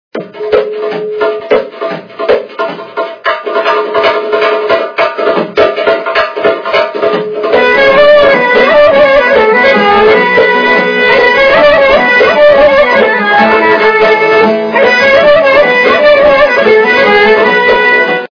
При прослушивании Стук - барабанов качество понижено и присутствуют гудки.
Звук Стук - барабанов